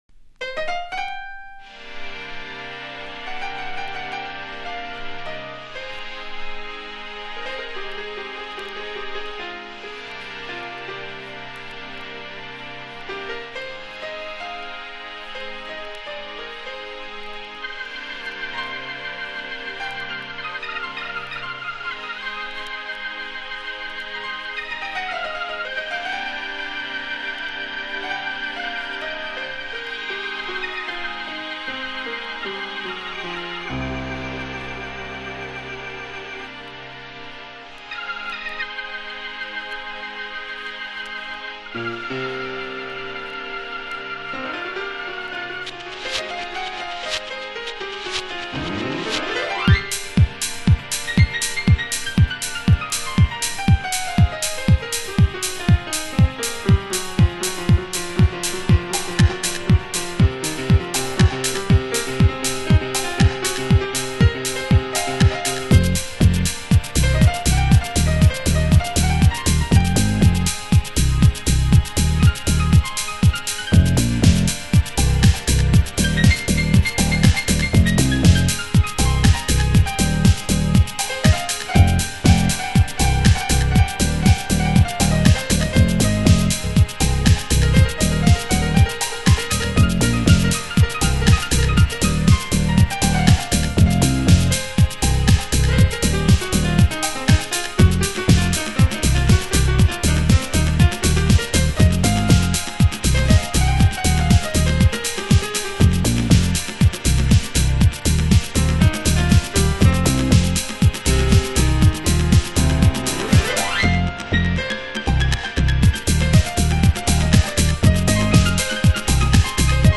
盤質：少しチリパチノイズ有